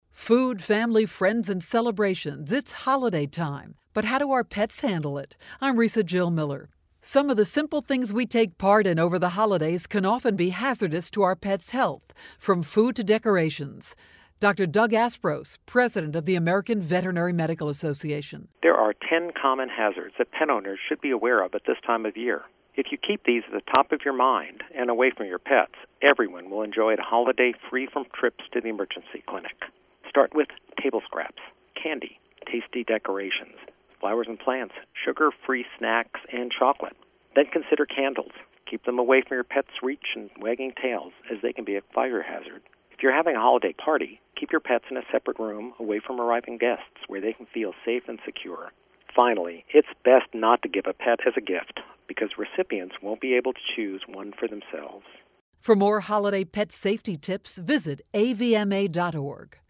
November 20, 2012Posted in: Audio News Release